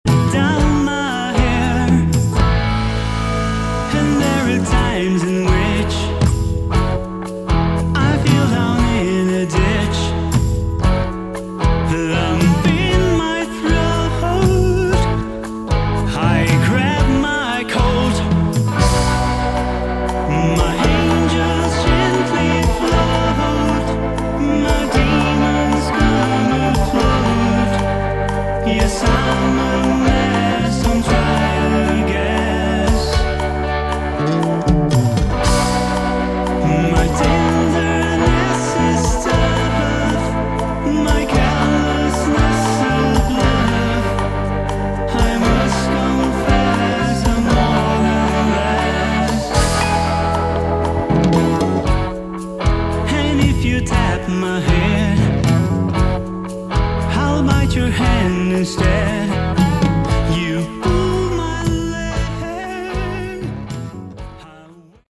Category: AOR / Melodic Rock
vocals
guitars
keyboards
bass
drums